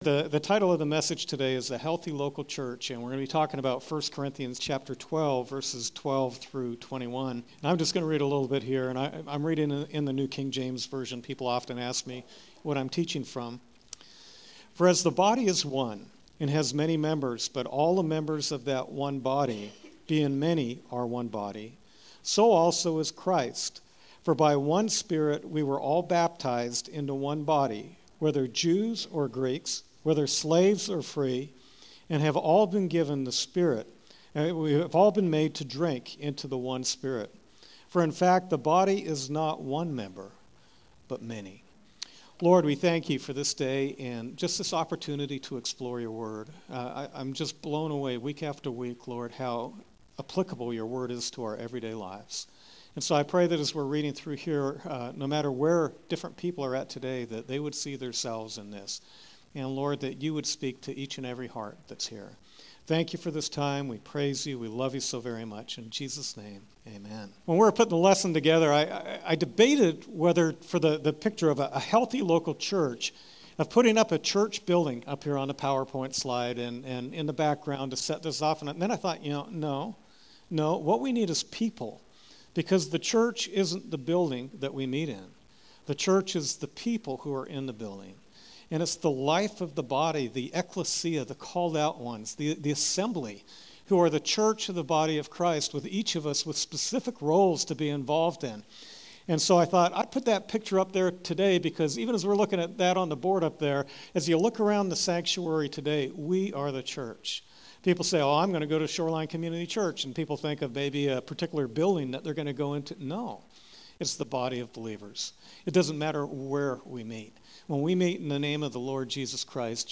Sermon Podcast “The Healthy Local Church” February 8, 2015